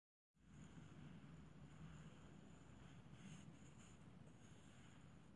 描述：Arrastrarse
Tag: 环境 atmophere 记录